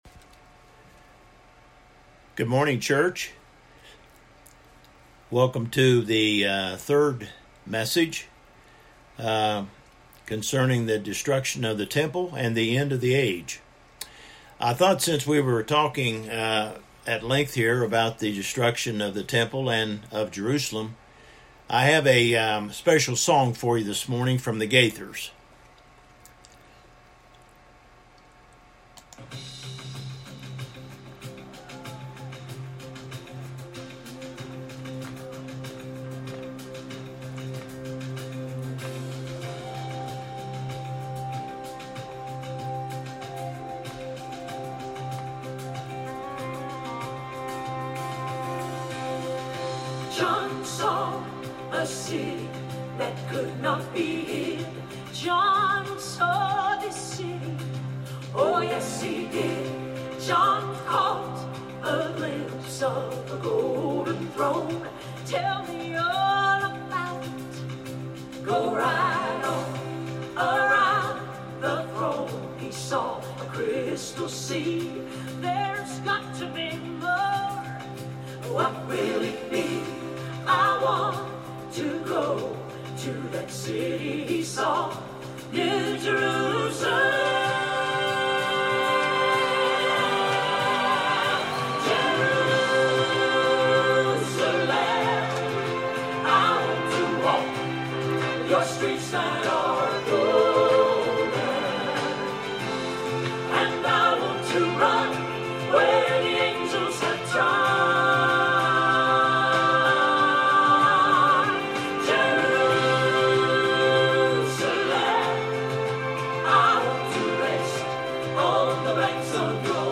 Passage: Luke 21:32-38 Service Type: Worship Service